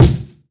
Groundhogs Day Kick.wav